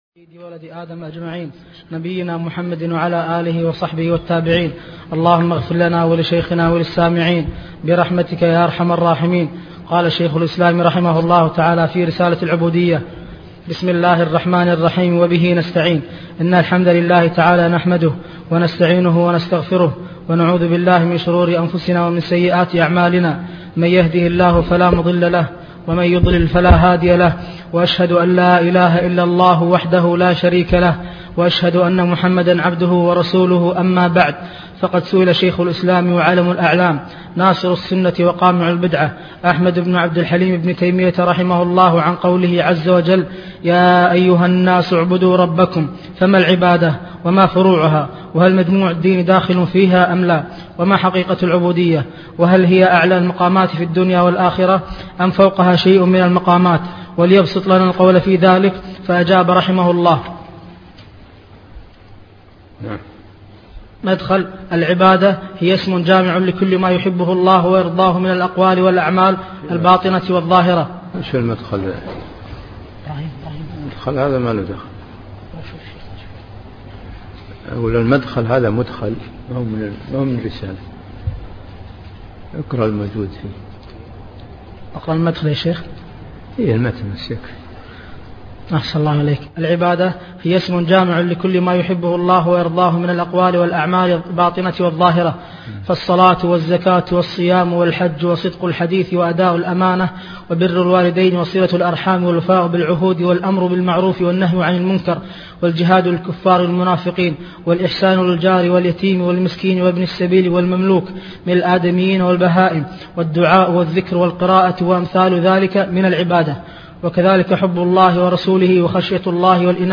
تفاصيل المادة عنوان المادة الدرس (1) شرح رسالة العبودية تاريخ التحميل الخميس 9 فبراير 2023 مـ حجم المادة 31.18 ميجا بايت عدد الزيارات 264 زيارة عدد مرات الحفظ 61 مرة إستماع المادة حفظ المادة اضف تعليقك أرسل لصديق